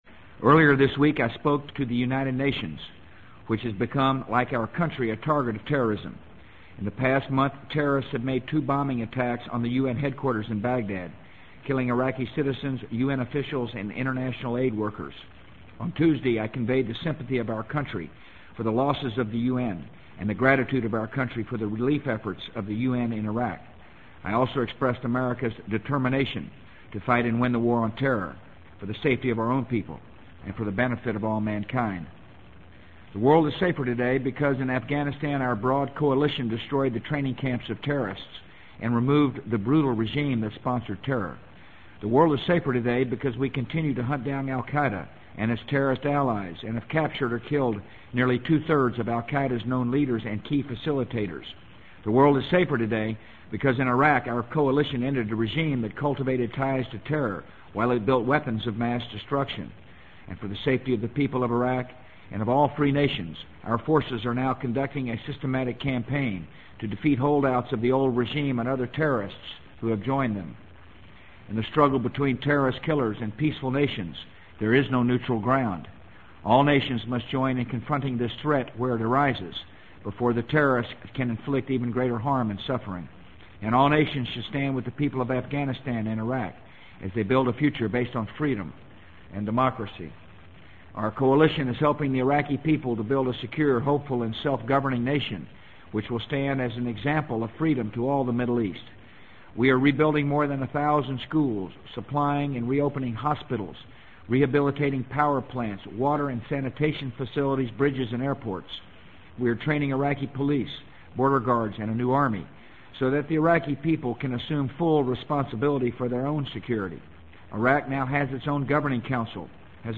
【美国总统George W. Bush电台演讲】2003-09-27 听力文件下载—在线英语听力室